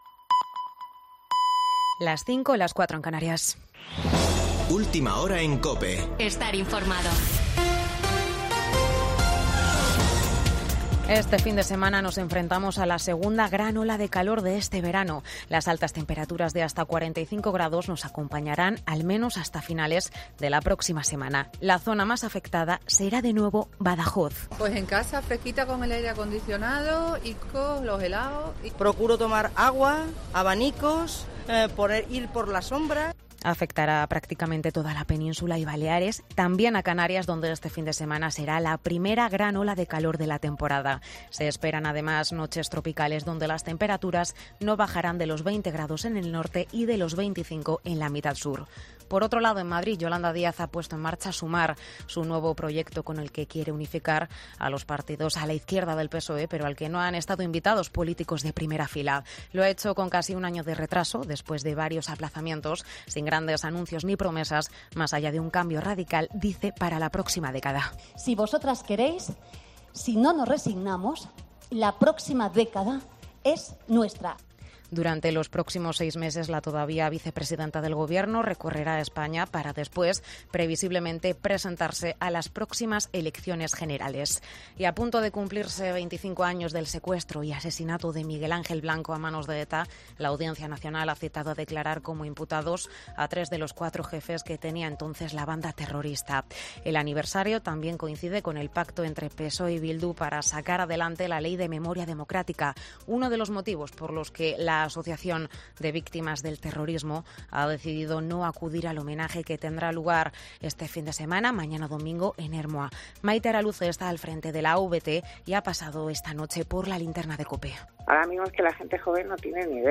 Boletín de noticias de COPE del 9 de julio de 2022 a las 05.00 horas